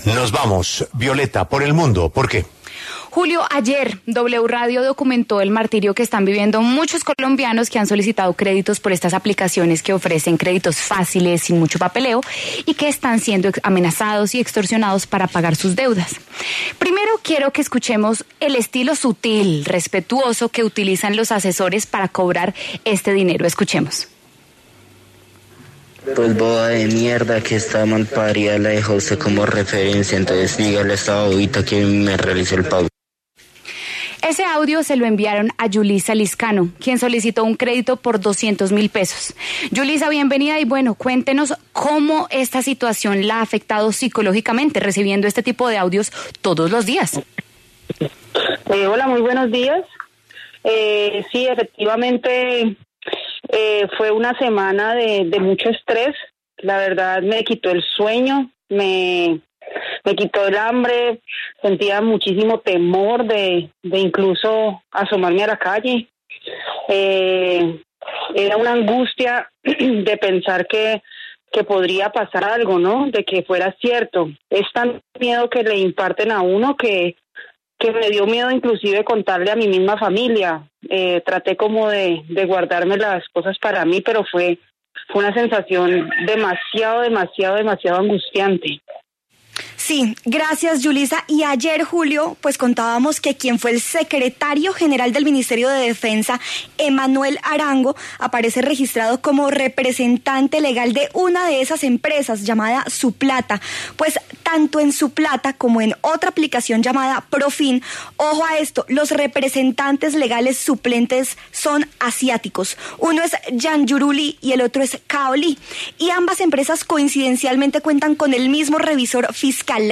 Claudia Sheinbaum, jefa de Gobierno de Ciudad de México, conversó en La W sobre esta modalidad de extorsión y el caso del call center en el que amenazaban a colombianos desde ese país.